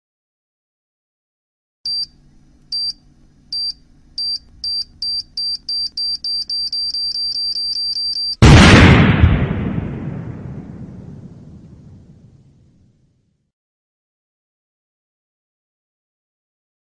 Suara Bom waktu
Kategori: Efek suara
Keterangan: Bom Meledak + Detikan, efek suara detik detik bom mau meledak...
suara-bom-waktu-id-www_tiengdong_com.mp3